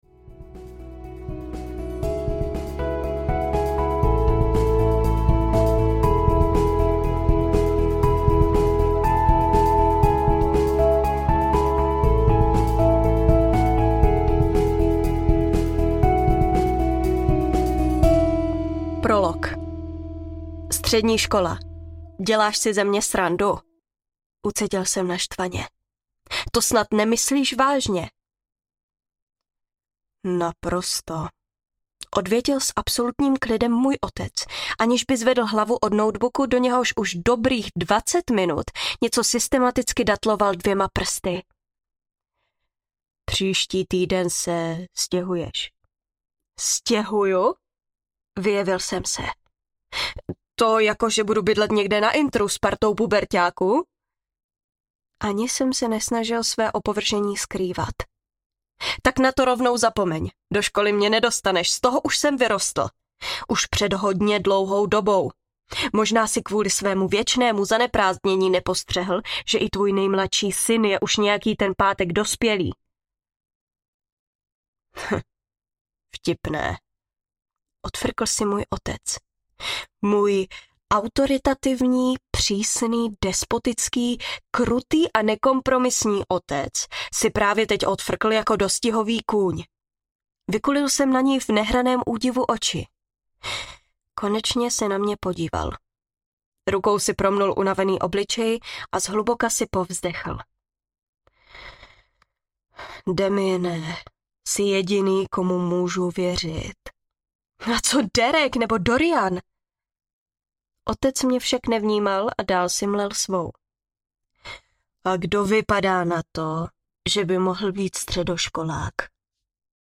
Tajemství obsidiánu audiokniha
Ukázka z knihy